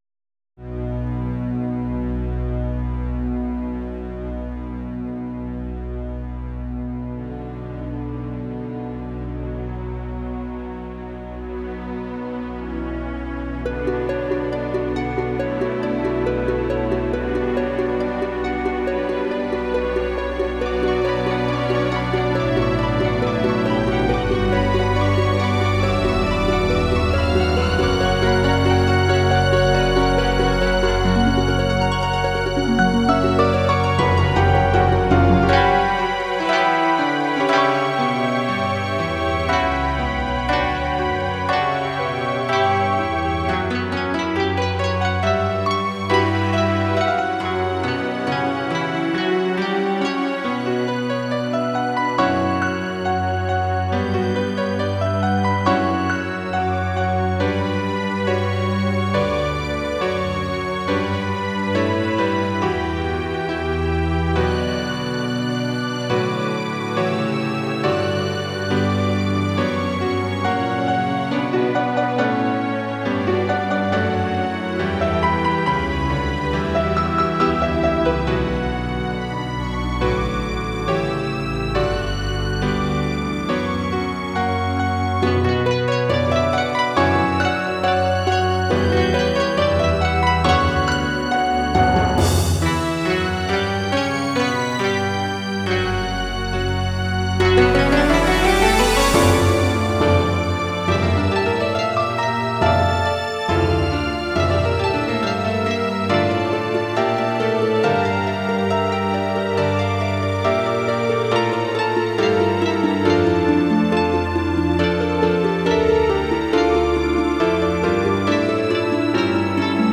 特別音楽会、大音楽会で合同演奏する曲の、練習用音源を掲載します。
音が取りやすいよう、テンポを落としている楽曲もあります。
シンセサイザー